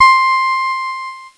Cheese Note 18-C4.wav